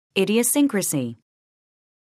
[ìdiəsíŋkrəsi]